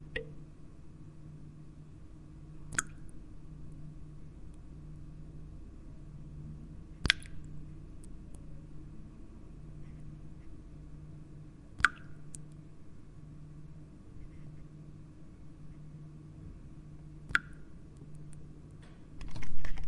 描述：厨房水龙头滴入水槽。Sennheiser MKH60 > Sound Devices 722.
标签： 现场记录 水槽 丝锥
声道立体声